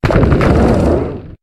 Cri de Géolithe dans Pokémon HOME.